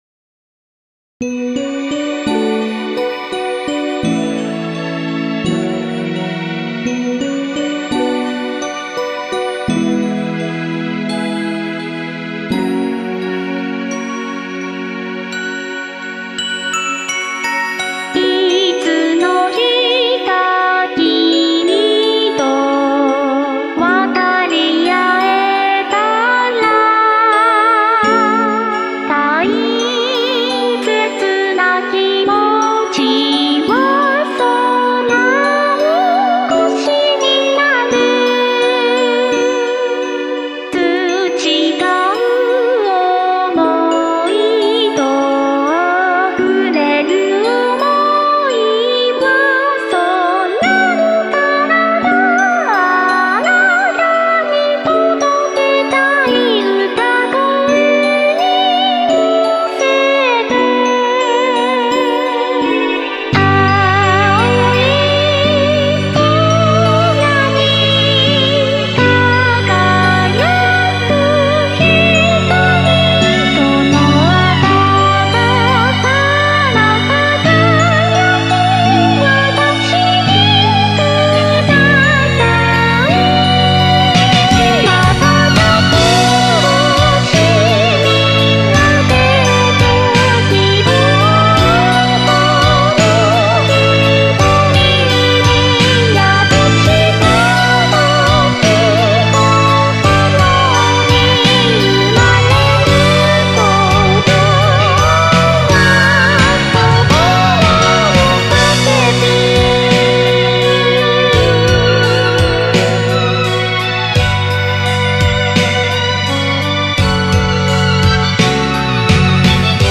という事で一番初期のボカロ２『初音ミク』で調教してみました！ｗｗｗ